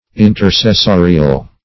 Intercessorial \In`ter*ces*so"ri*al\, a.
intercessorial.mp3